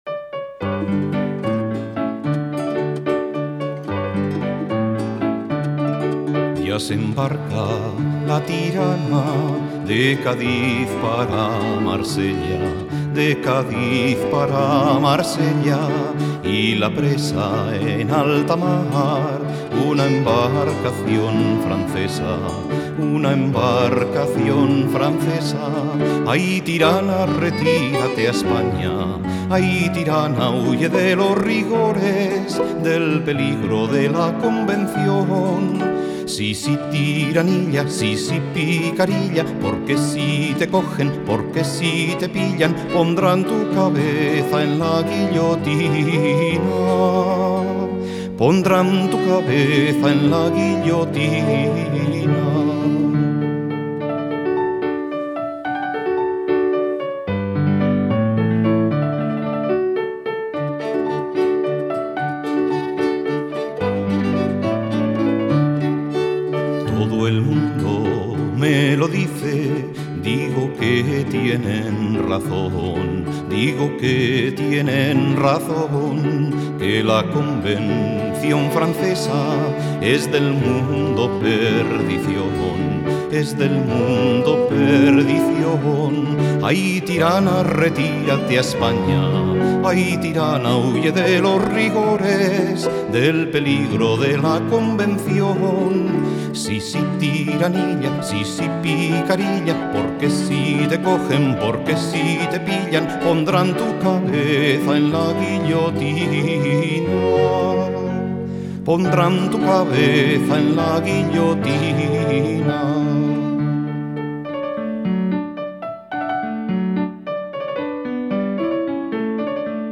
Piano
Violín